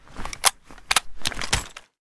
l85_switch.ogg